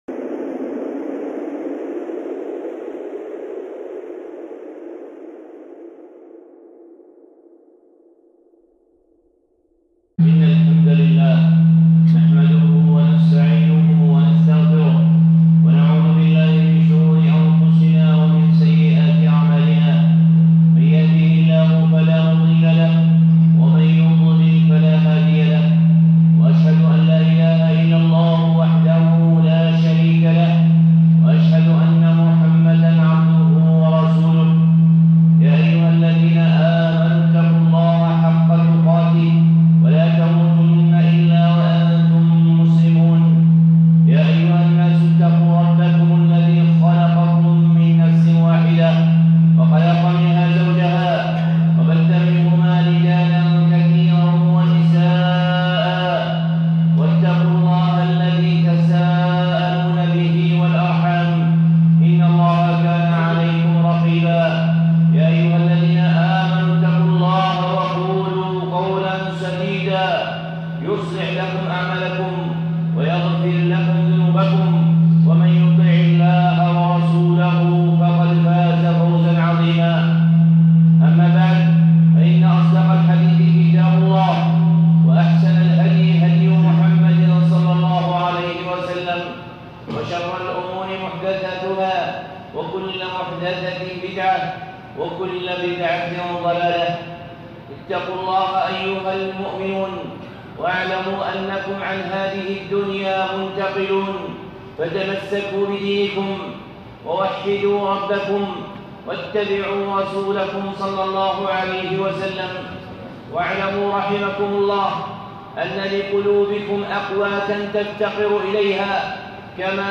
خطبة (أحب الكلام إلى الله)